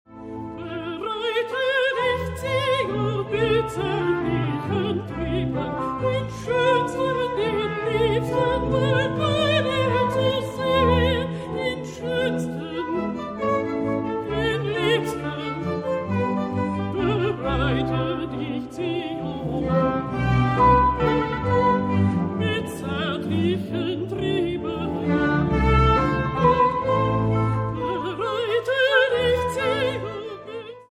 Festliche Advents- und Weihnachtsmusik
Vocal- und Orgelmusik Neuerscheinung 2010